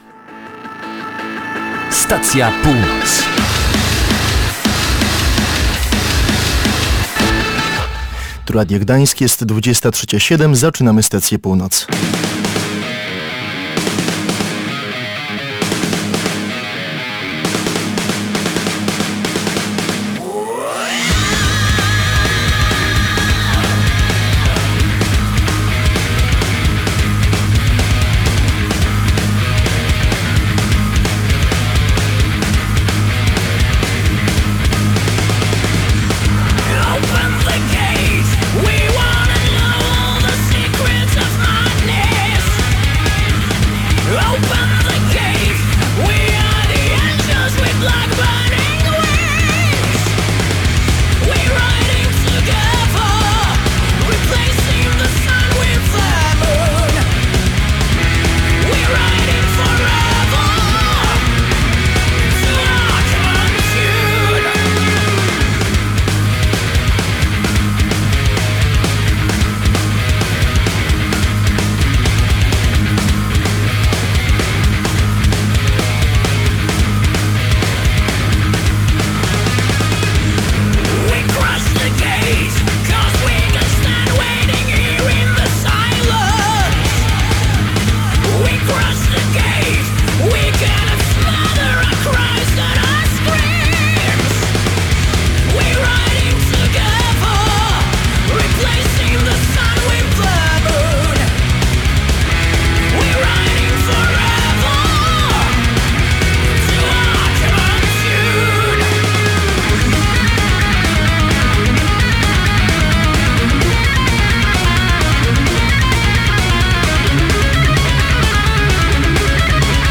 W tym wydaniu Stacji Północ rozmowa z muzykami zespołu Slovenian Girl z Wejherowa.